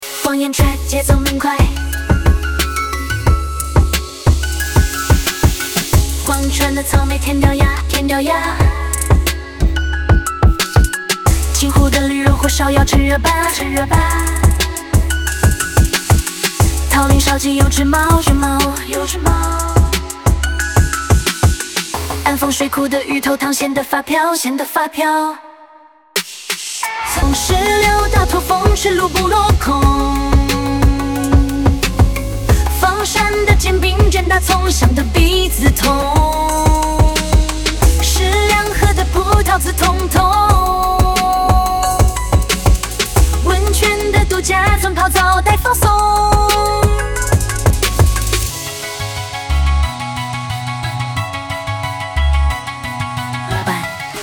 （曲风：方言 trap，节奏明快）
人工智能生成式歌曲